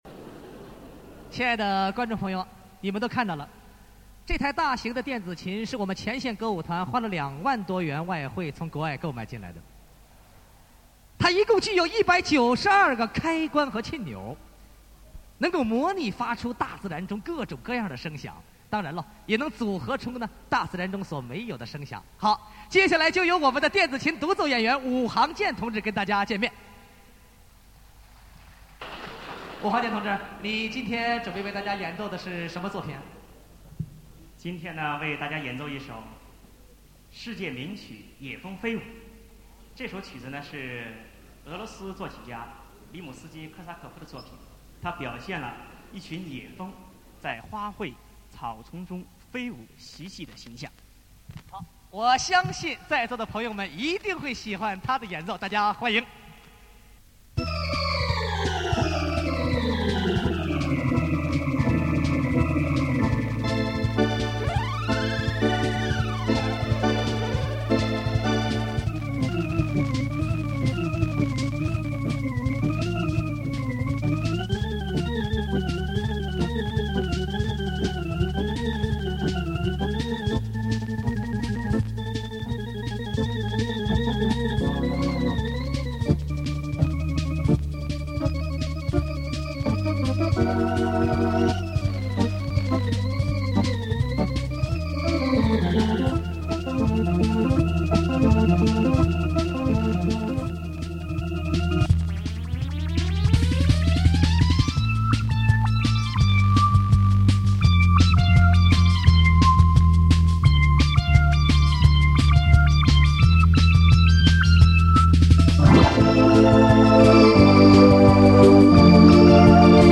电子琴演奏和音乐创作。
dianziqin.mp3